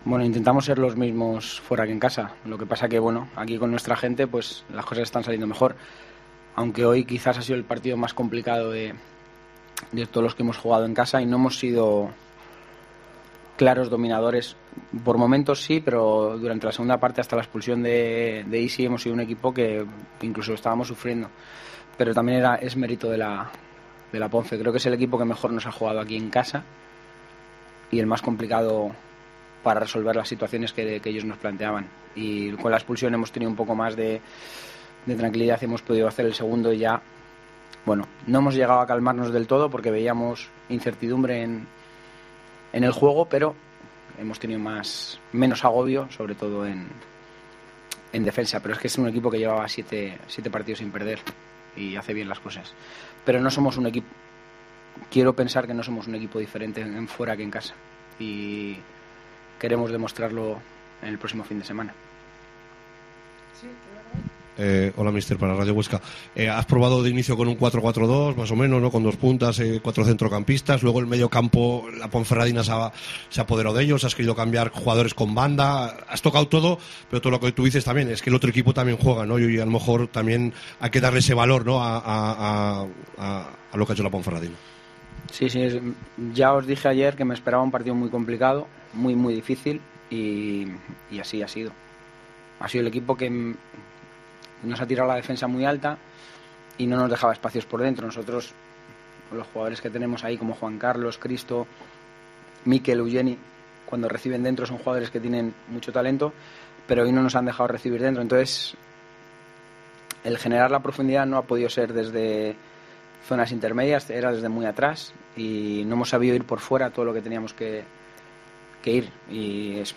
AUDIO: Escucha aquí las palabras del entrenador del Huesca tras la victoria 2-0 ante la Ponferradina